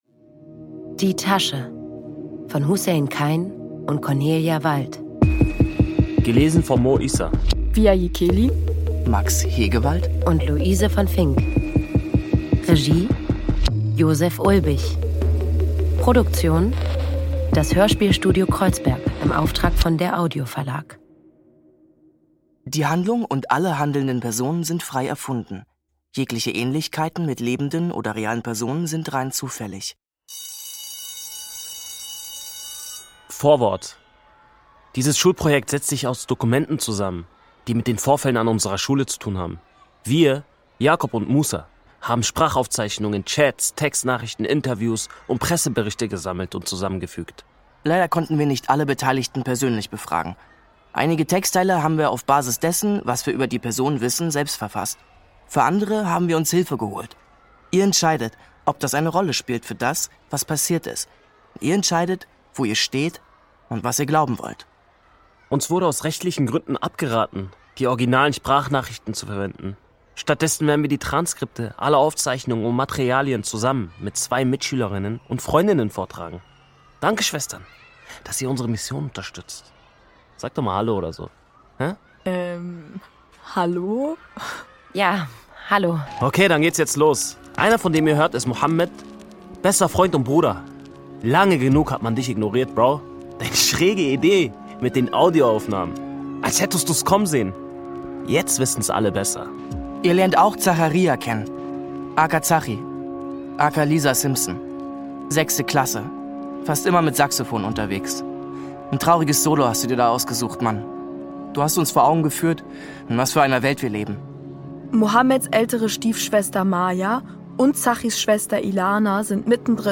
Ungekürzte szenische Lesung mit Musik